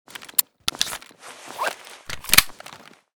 fn57_reload.ogg